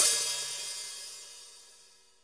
• Perfect Pedal Hi-Hat Single Hit C# Key 130.wav
Royality free hi-hat sample tuned to the C# note. Loudest frequency: 10831Hz
perfect-pedal-hi-hat-single-hit-c-sharp-key-130-mh0.wav